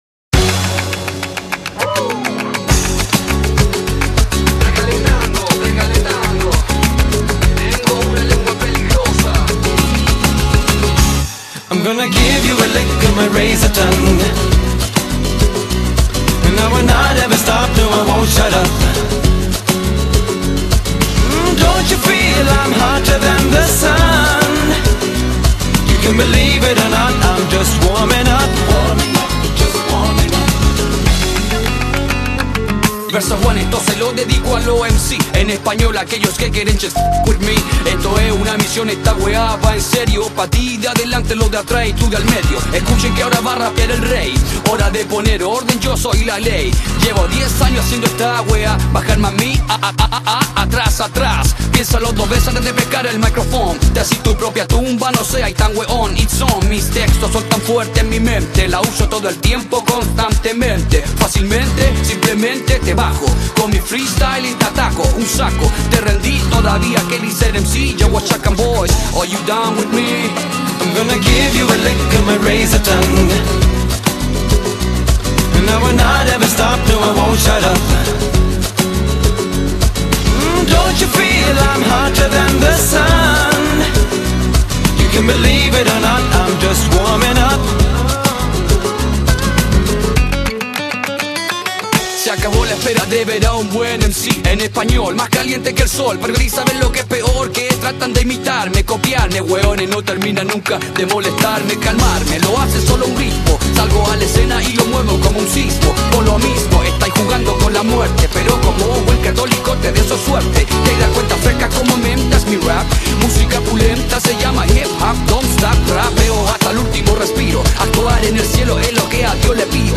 08 Samba